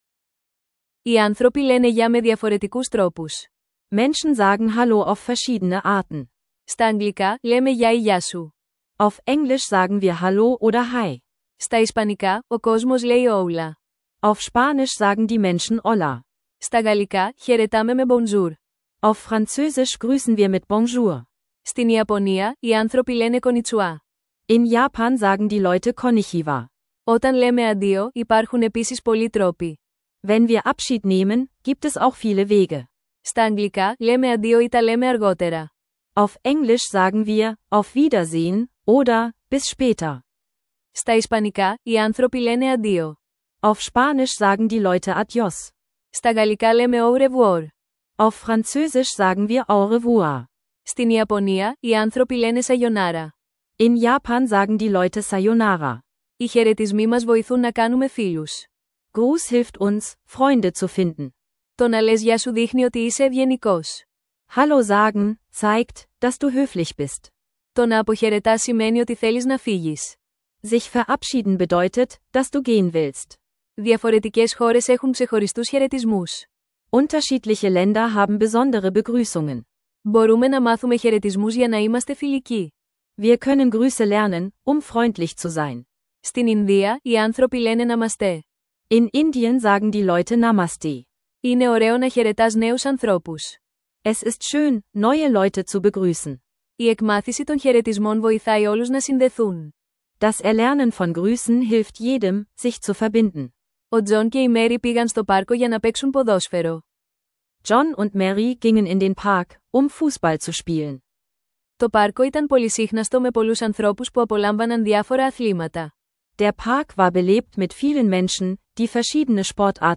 In dieser Episode unseres SynapseLingo Griechischkurses lernen Sie einfache Gruß- und Abschiedsphrasen aus verschiedenen Ländern kennen. Ideal für Anfänger, die Griechisch mit Audiolektionen und interaktivem Lernangebot einfach und kostenlos online lernen möchten.